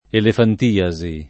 elefantiasi [ elefant & a @ i ] s. f.